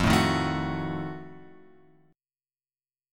F7b9 chord {1 0 1 2 x 2} chord